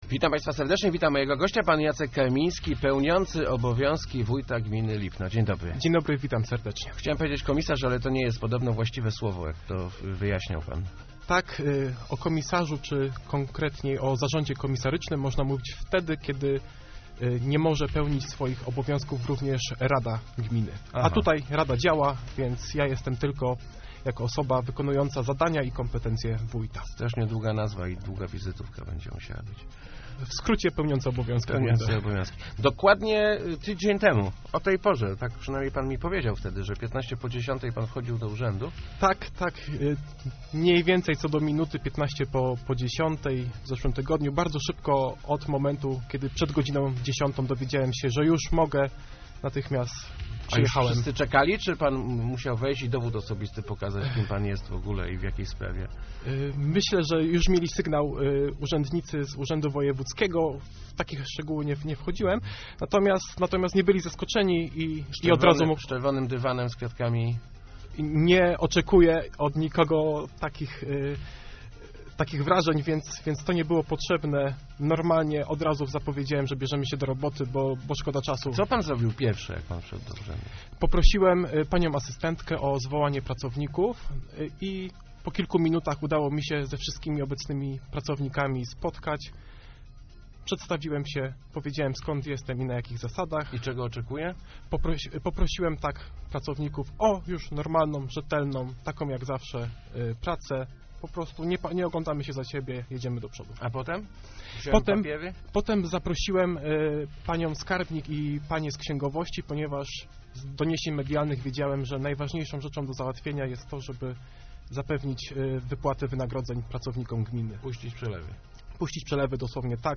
Zastałem w urzędzie zaniedbania - przyznał w Rozmowach Elki Jacek Karmiński, pełniący obowiązki wójta Lipna.